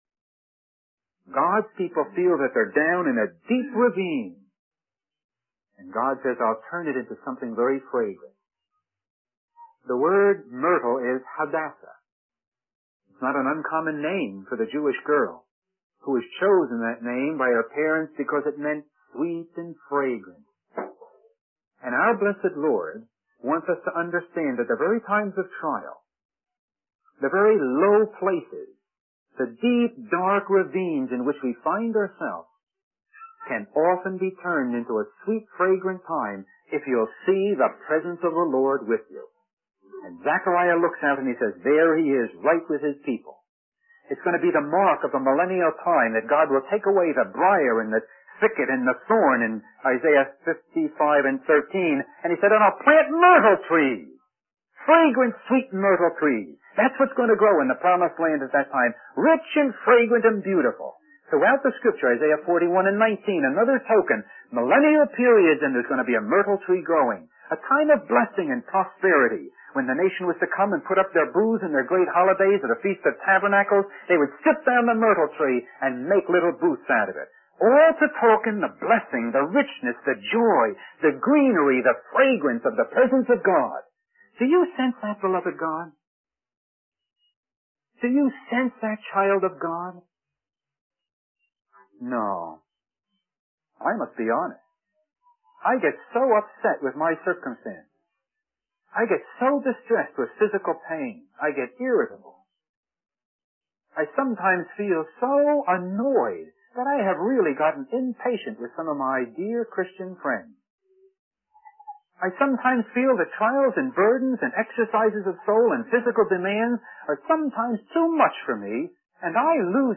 Mid South Conference 1978-08 Zechariah's Visions
In this sermon, the speaker addresses the challenges and distractions that prevent people from prioritizing their relationship with God. He emphasizes the importance of recognizing God's personal care and seeking His guidance in troubled times.